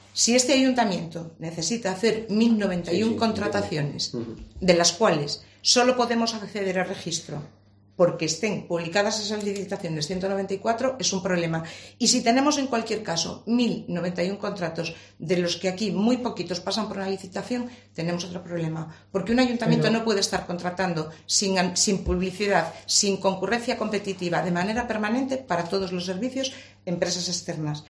Declaraciones de Esther Llamazares